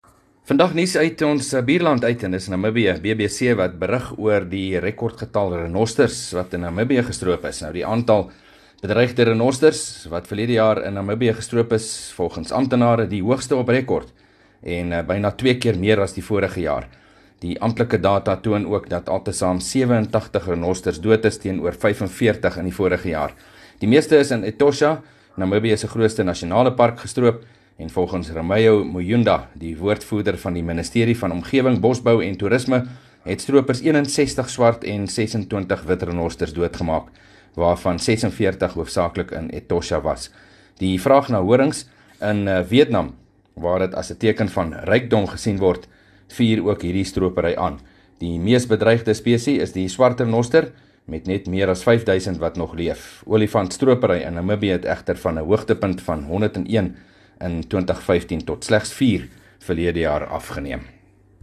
2 May PM berig oor renosterstroping in Namibië